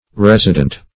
Resident - definition of Resident - synonyms, pronunciation, spelling from Free Dictionary
Resident \Res"i*dent\ (-dent), a. [F. r['e]sident, L. residens,